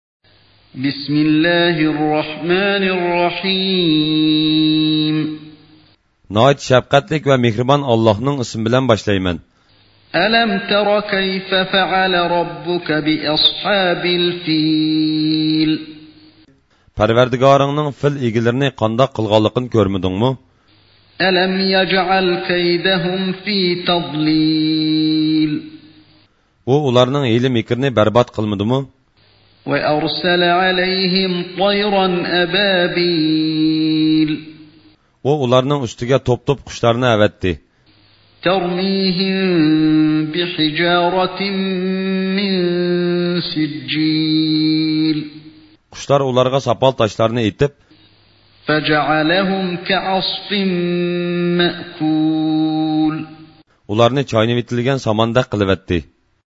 Category: Recitations with Translation